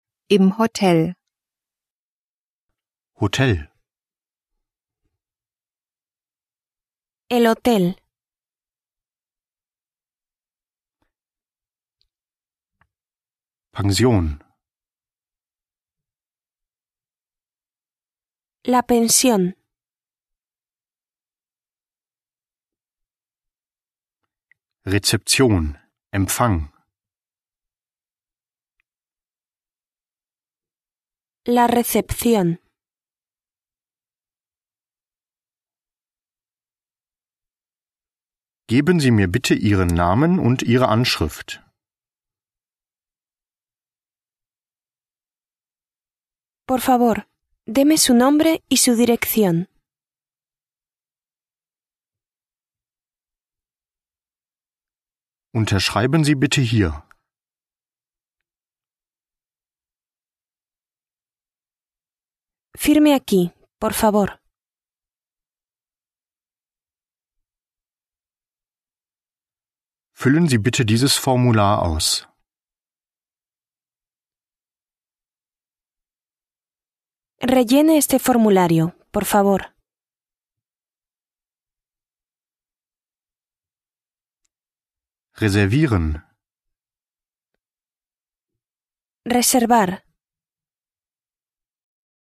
Übersetzungs- und Nachsprechpausen sorgen für die Selbstkontrolle.